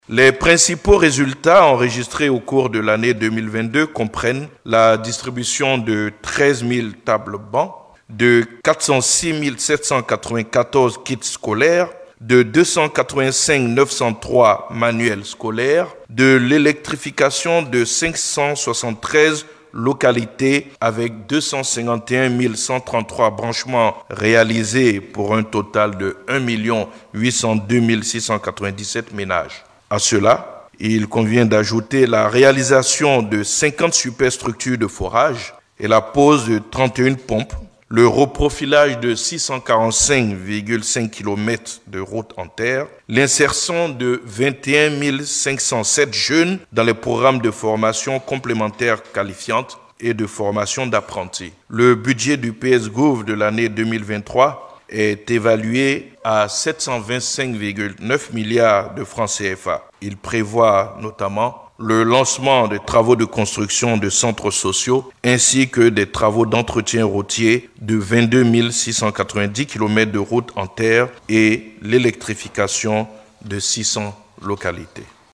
On écoute un extrait de l’intervention du Porte-parole du Gouvernement, Amadou COULIBALY sur le sujet
amadou-coulibaly-porte-parole-du-gouvernement-bilan-psgouv-fin-2022.mp3